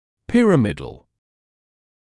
[‘pɪrəmɪdl][‘пирэмидл]пирамидальный, пирамидный